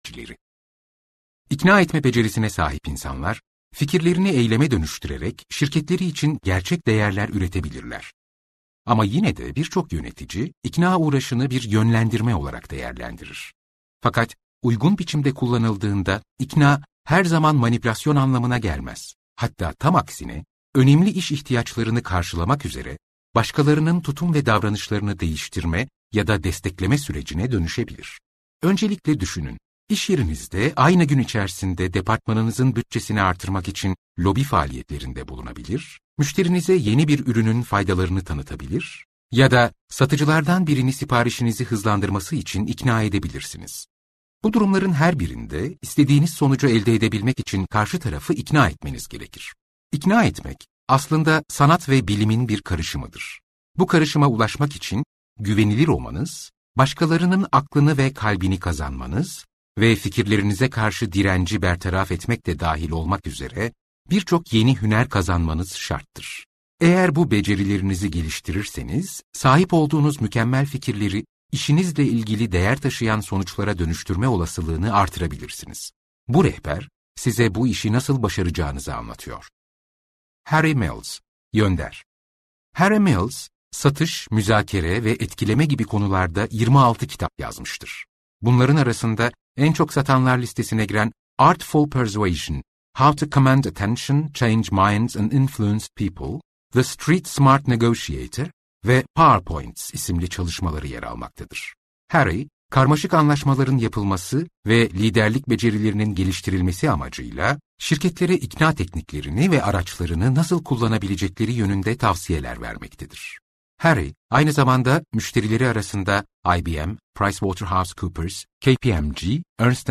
İnsanları İkna Etmek - Seslenen Kitap